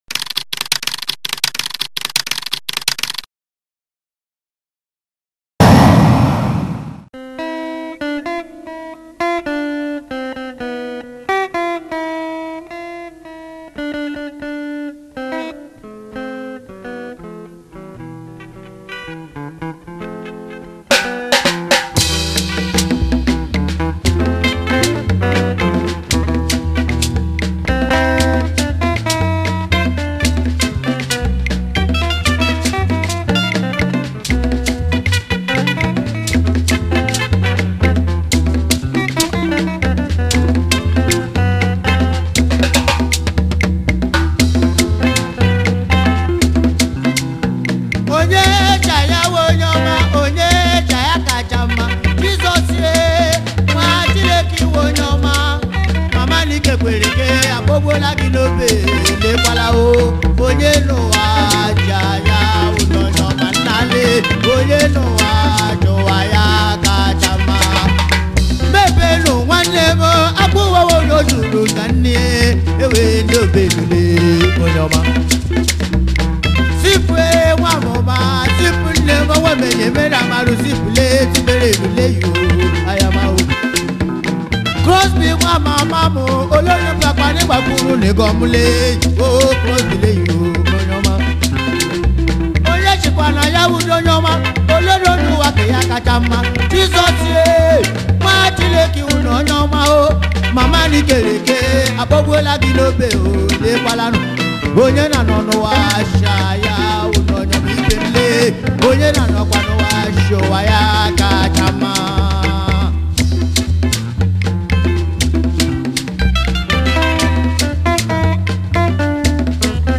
was a Nigerian orchestra high life band from Eastern Nigeria